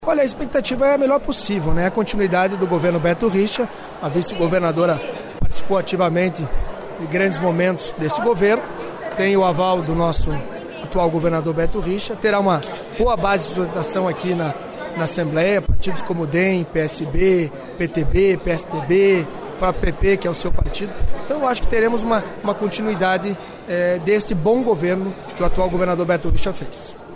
Ouça entrevista do deputado Alexandre Curi (PSB) falando sobre expectativas para o Governo Cida Borghetti (PP).(Sonora)